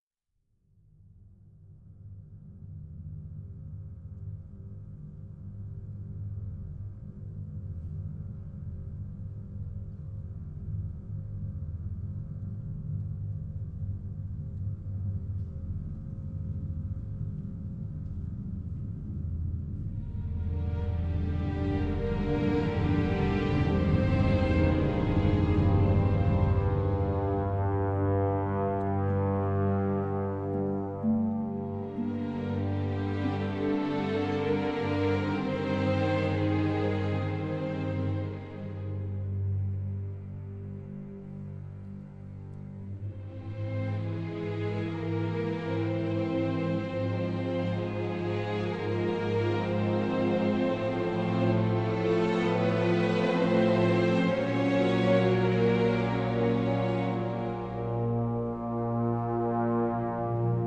jazz compositions